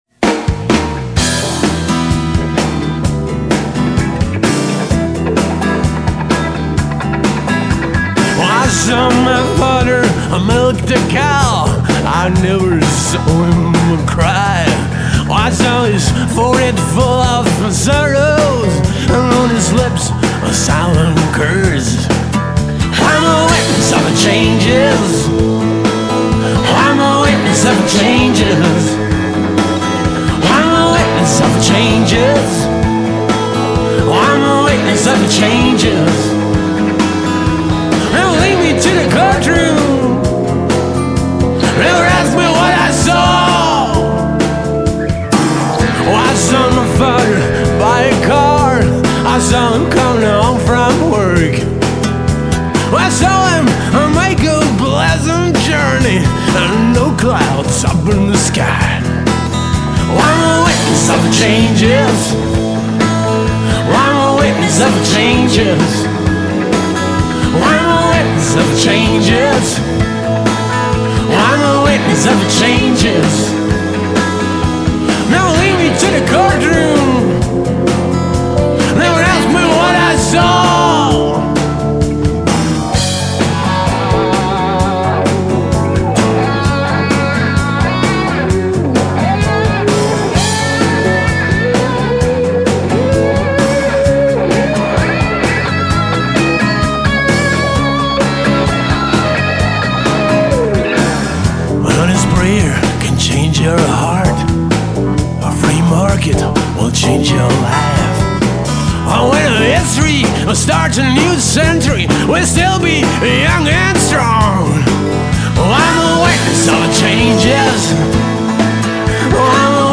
Aufnahmejahr: 2002/2003, verschiedene Aufnahmeorte
drums
bass
acoustic and electric guitars
Fender Rhodes
harmony vocals
vocals